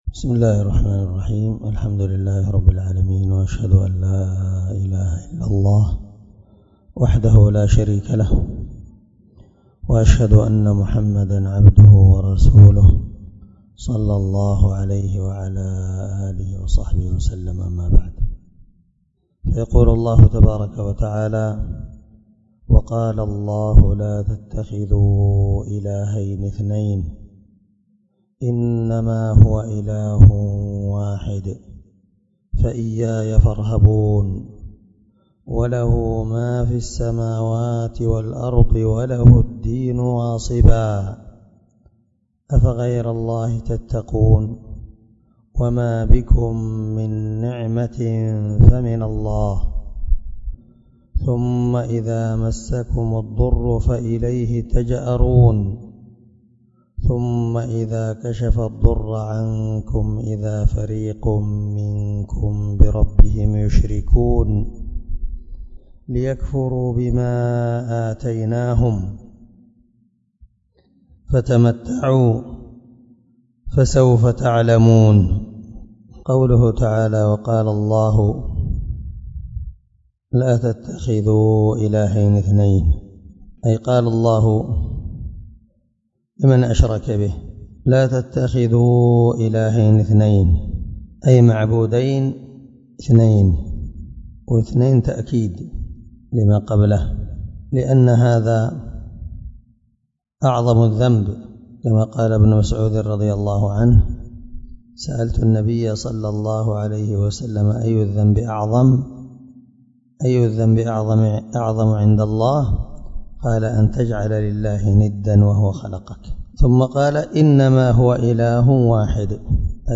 الدرس 15 تفسير آية (51-55) من سورة النحل